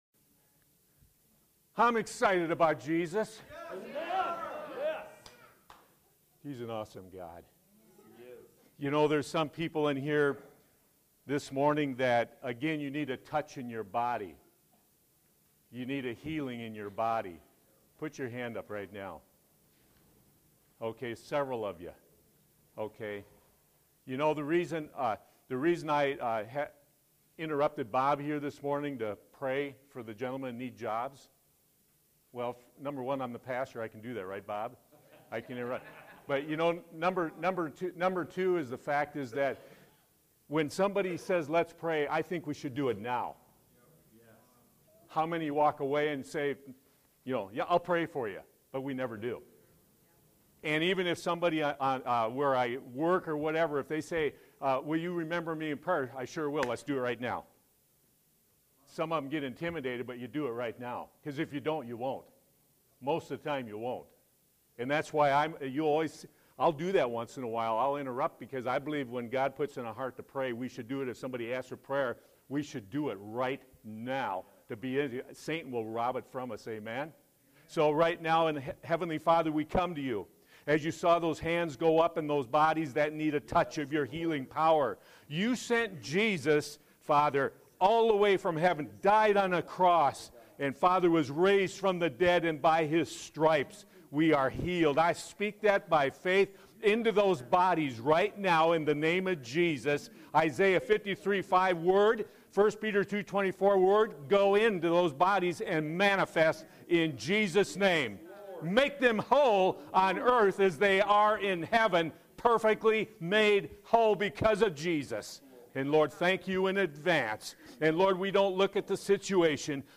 Sermons Archive - Page 63 of 68 - Crossfire Assembly